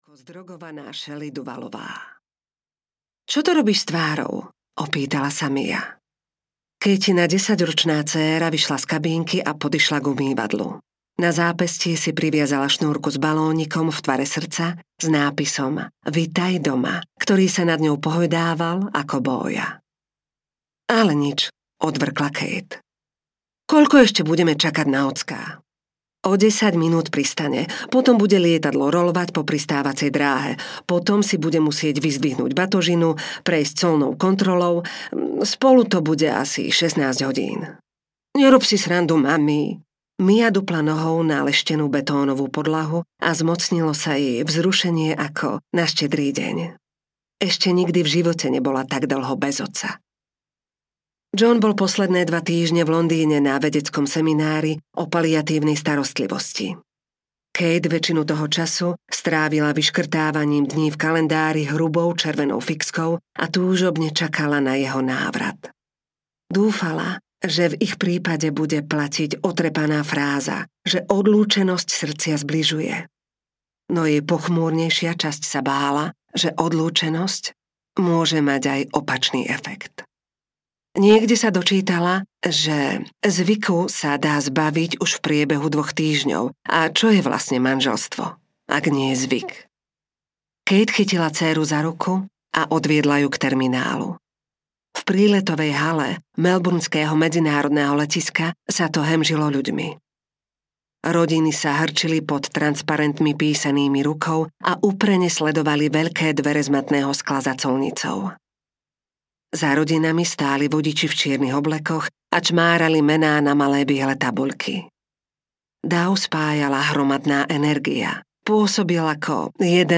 Manželka a vdova audiokniha
Ukázka z knihy